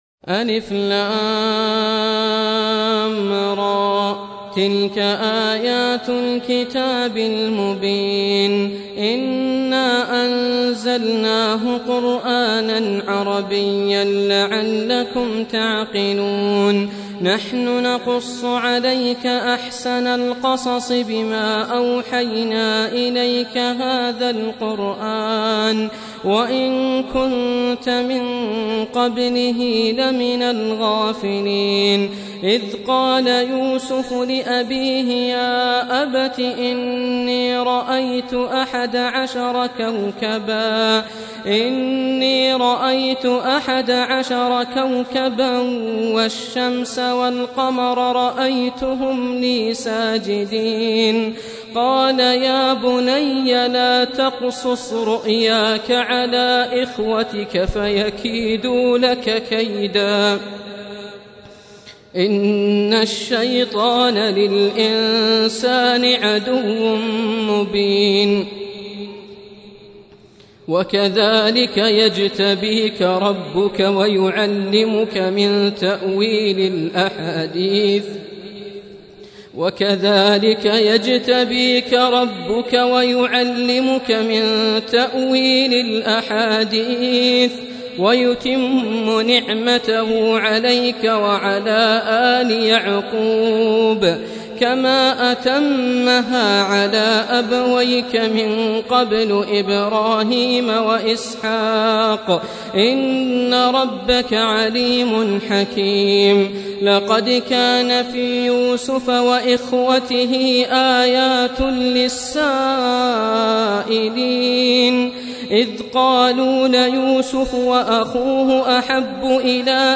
Surah Repeating تكرار السورة Download Surah حمّل السورة Reciting Murattalah Audio for 12.